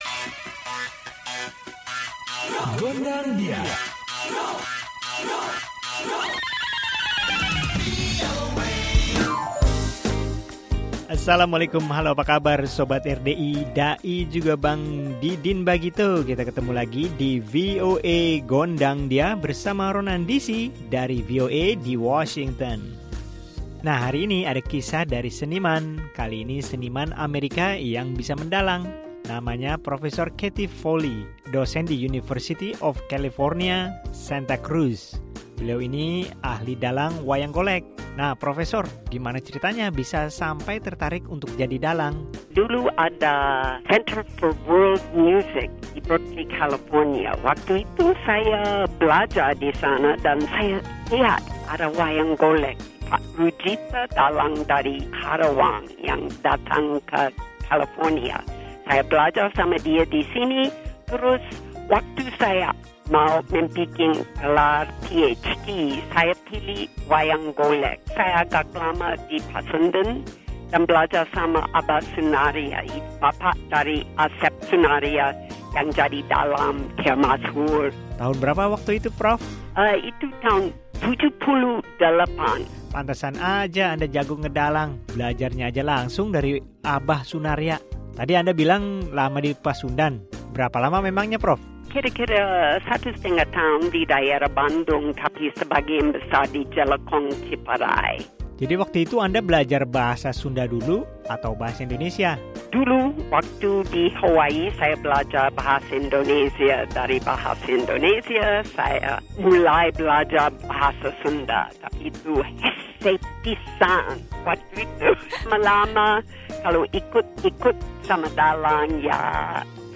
Masih mengenai kesenian Indonesia di Amerika, kali ini ada obrolan bersama seorang dalang wayang Golek dari Amerika